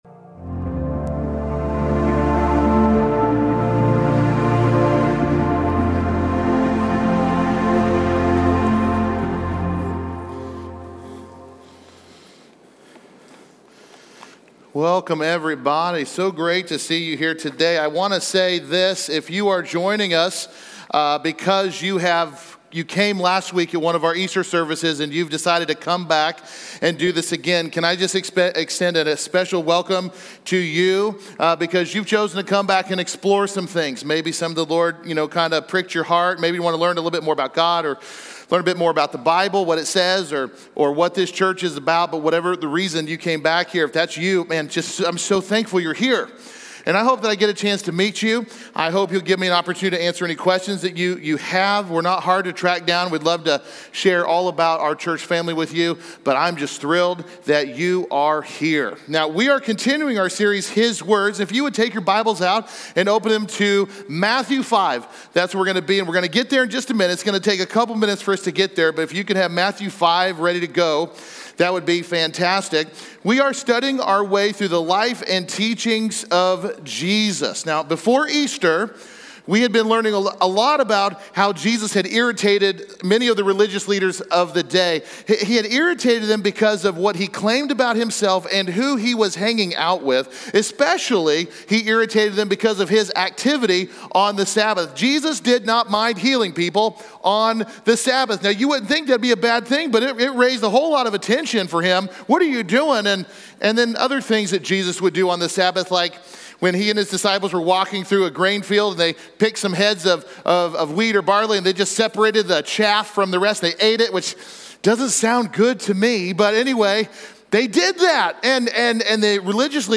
Join us as we dive into Jesus' transformative teachings from the Sermon on the Mount, exploring how the Beatitudes can shape our daily lives and help us live counter-culturally. Whether you're new to faith or seeking deeper insights, you'll find valuable takeaways from today's sermon.